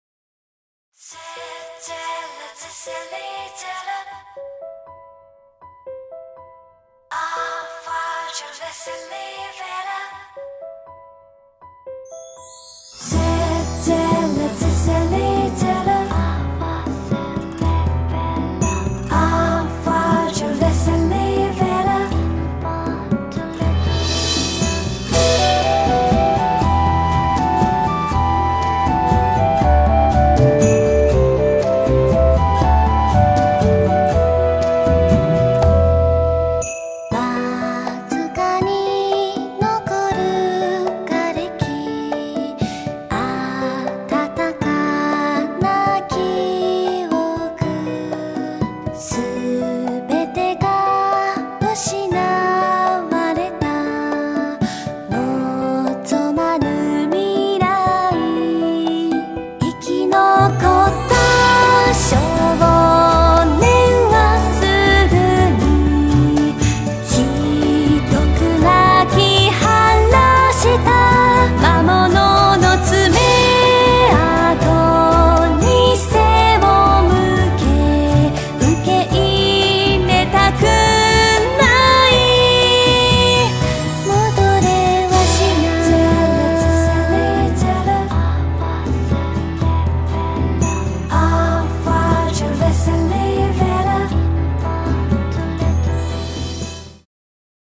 10曲入り オリジナルボーカルアルバム
多重録音コーラスと民族調RPGサウンド